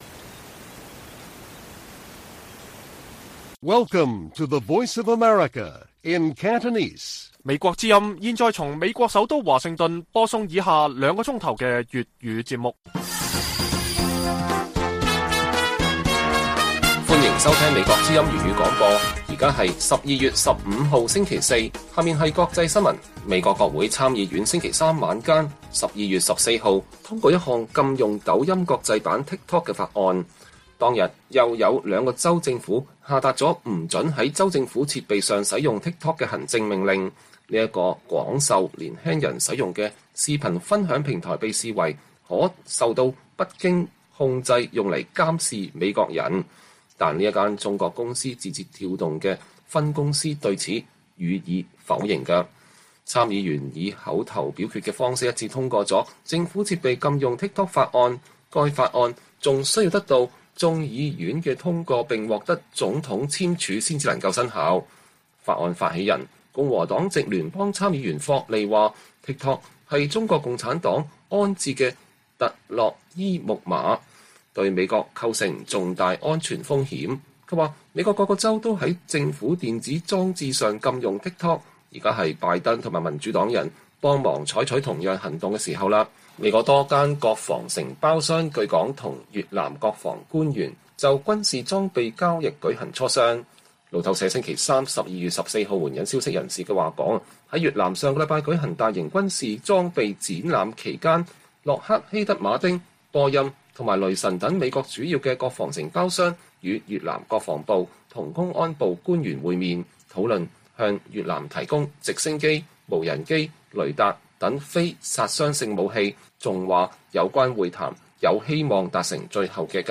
粵語新聞 晚上9-10點: 美國國會參院通過政府設備禁用TikTok法案